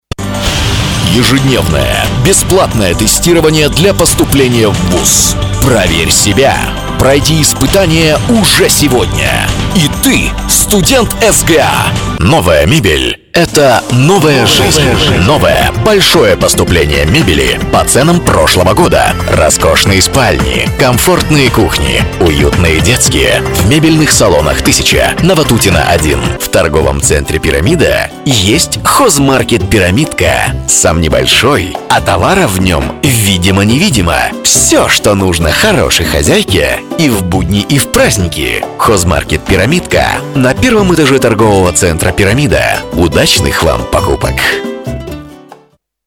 УНИВЕРСАЛЬНЫЙ диктор. Артист *игровых* ролей -от комедии до драмы, и наоборот.
RODE 1000,Neumann 87